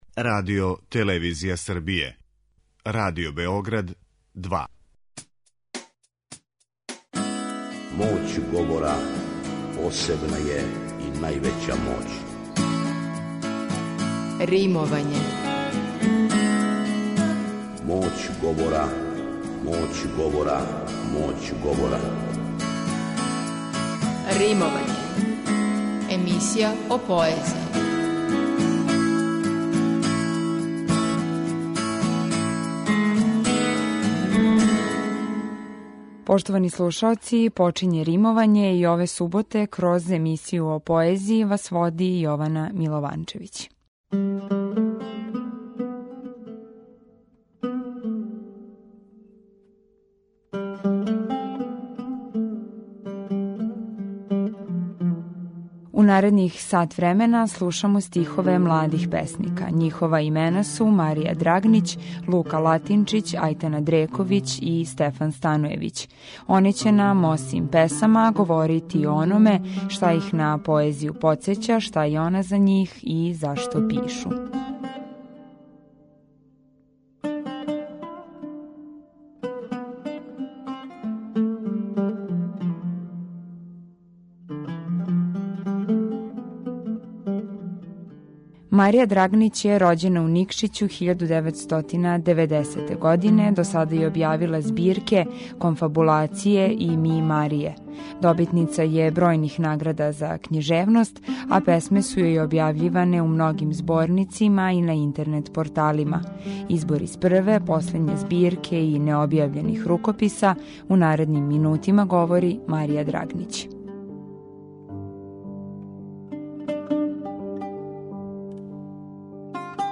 Они ће нам говорити стихове и поделити са нама своје мисли о поезији и смислу стварања.